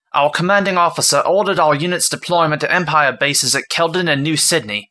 Human Male, Age 28